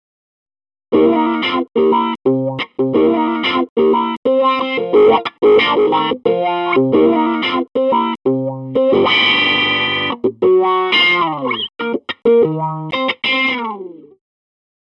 「Electric Guitar」
「１．オリジナル・パート」 プリセットの“WahWah”を使用 （MP３ 224KB）